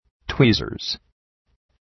Προφορά
{‘twi:zərz}